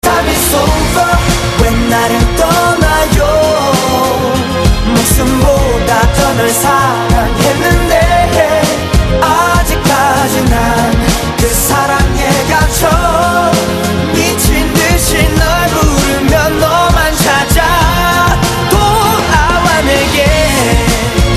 M4R铃声, MP3铃声, 日韩歌曲 94 首发日期：2018-05-15 20:40 星期二